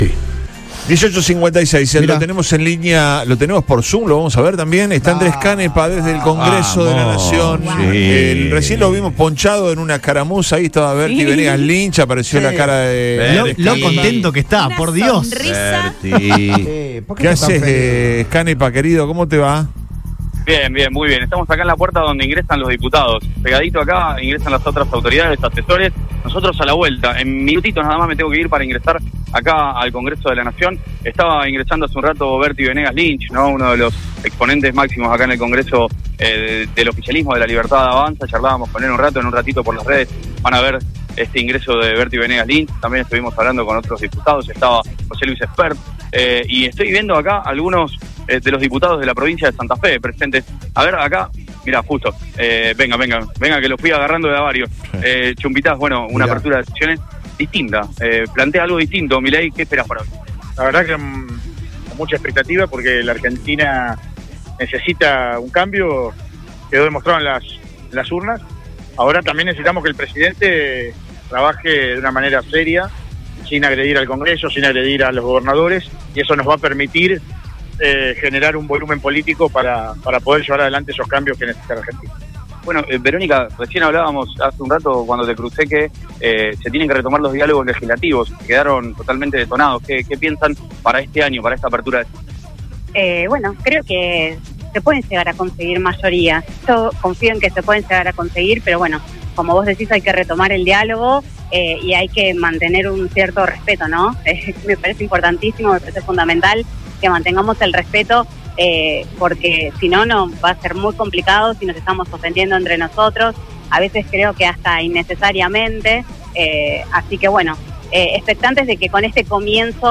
Javier Milei dará inicio a las sesiones ordinarias del Congreso de la Nación a las 21 horas del viernes. Previo al discurso presidencial, Radio Boing estuvo presente en el lugar y pudo conversar con algunos legisladores antes del ingreso al recinto.